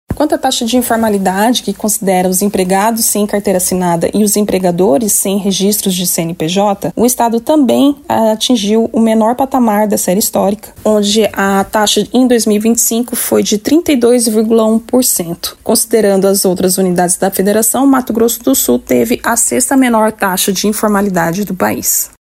Em entrevista ao programa “Agora 104” da FM Educativa MS